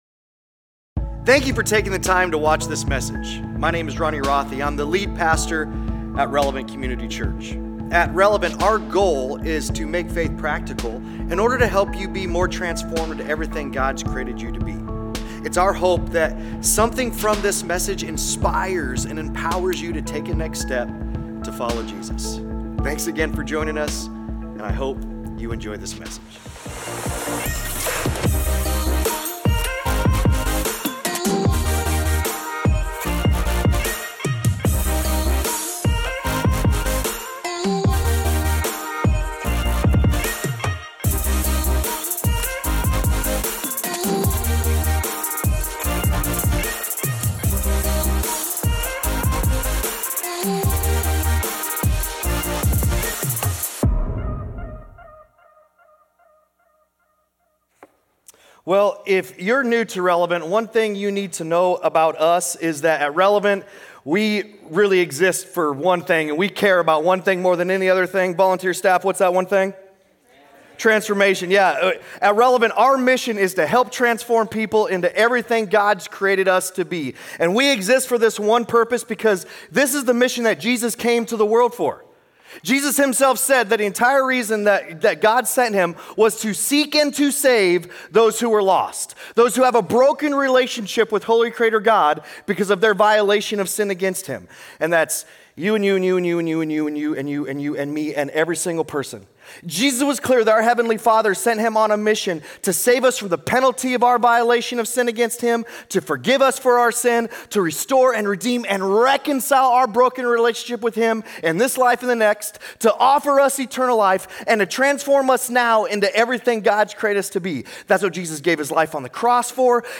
Weekly sermons from Relevant Community Church in Elkhorn, NE.